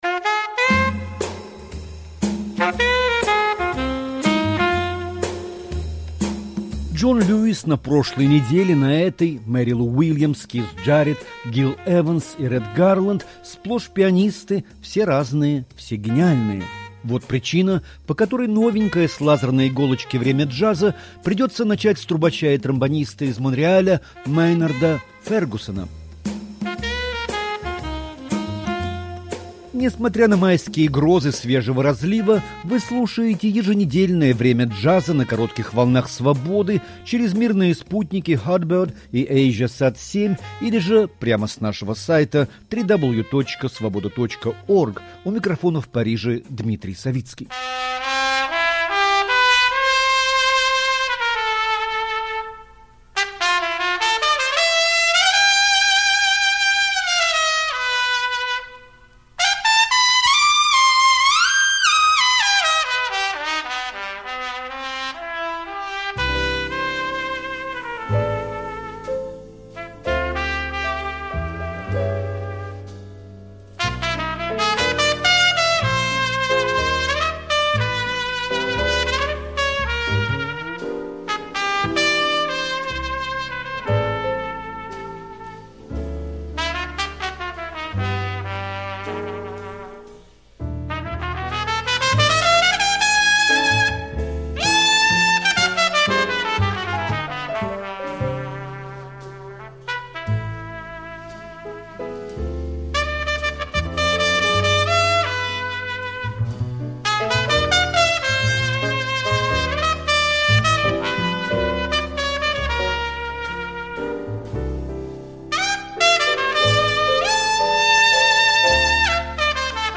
Время джаза. От звонкой трубы до буги-вуги и классического хард-бопа